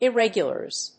発音記号・読み方
/ˌɪˈrɛgjʌlɝz(米国英語), ˌɪˈregjʌlɜ:z(英国英語)/